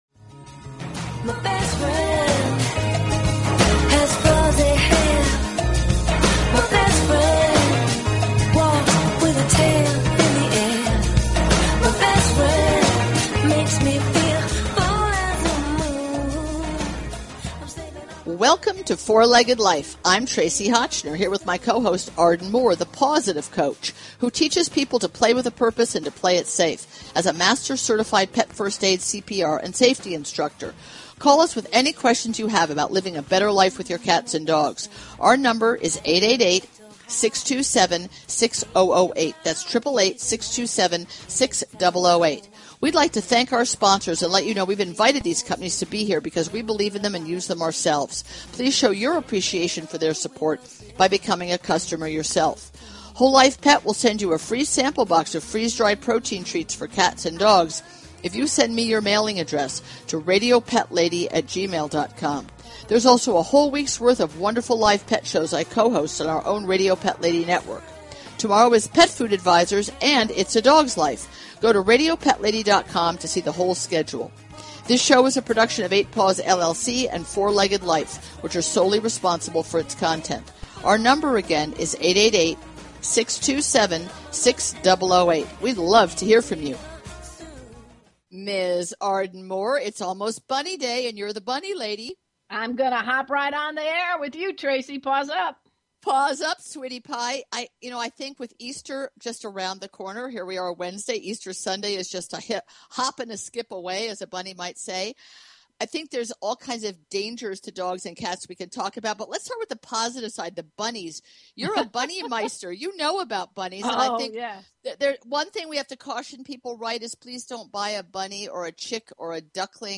Talk Show Episode, Audio Podcast, Four-Legged_Life and Courtesy of BBS Radio on , show guests , about , categorized as